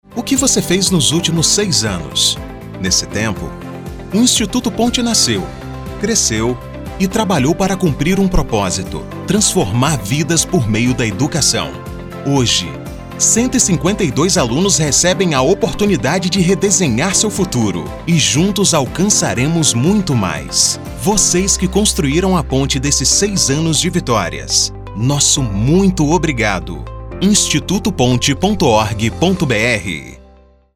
Spots comemorativos veiculados na Rádio Band News:
Spot-6-anos-do-Instituto-Ponte-24-de-agosto.mp3